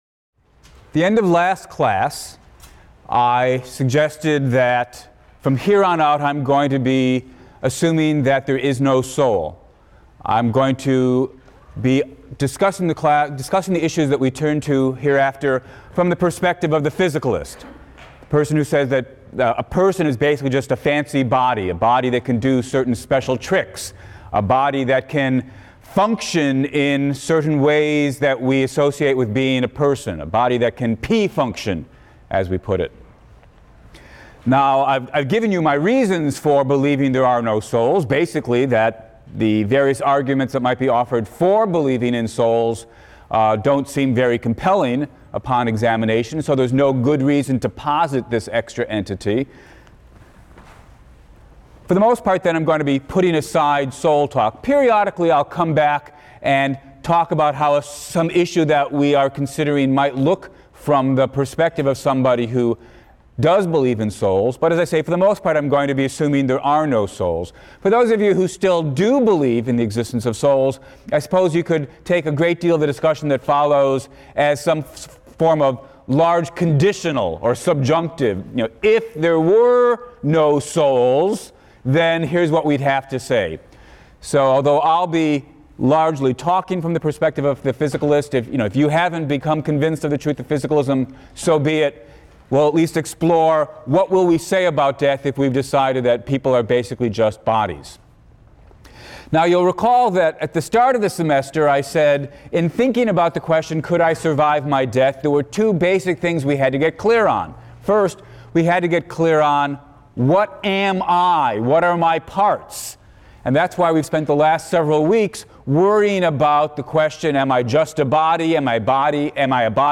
PHIL 176 - Lecture 10 - Personal Identity, Part I: Identity Across Space and Time and the Soul Theory | Open Yale Courses